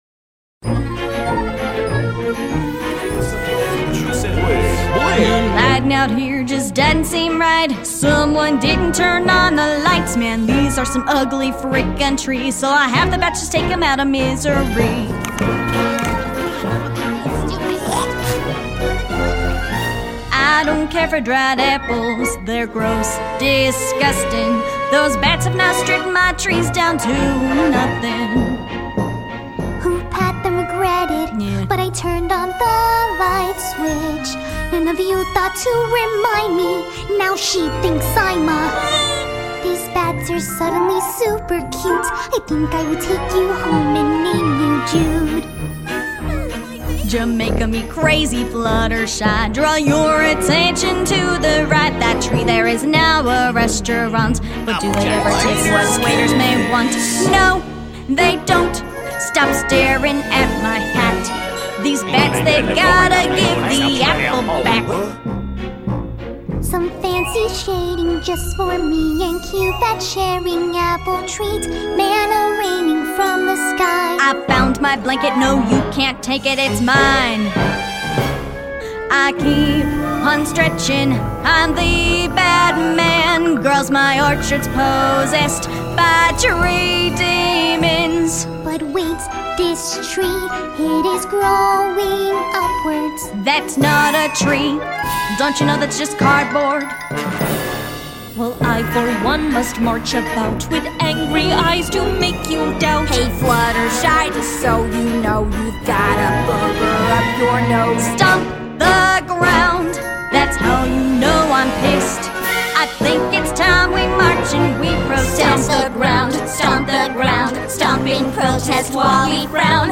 Ever wish ponies just sang what was happening in the video?